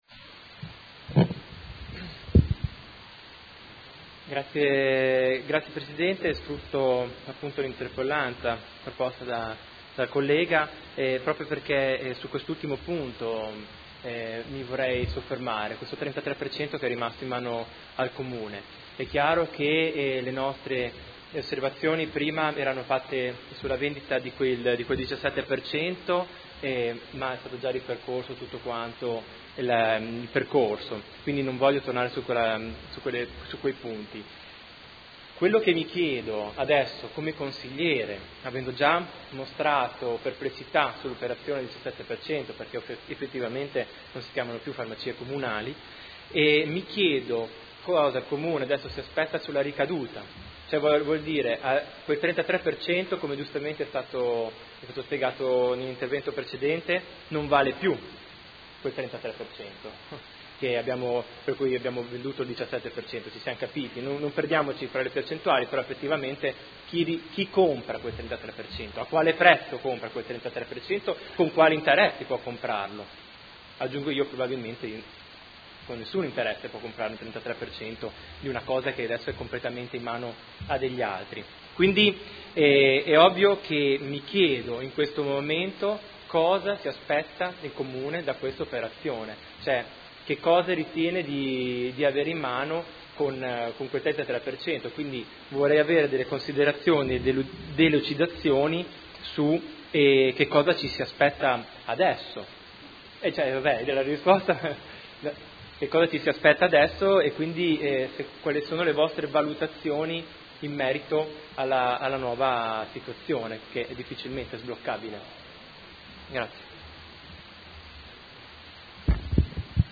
Seduta del 22 ottobre. Interrogazione del Gruppo Consiliare Movimento 5 Stelle avente per oggetto: Informazioni a corredo del bando di vendita azioni Farmacie Comunali.